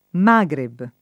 Magreb [ m #g reb ]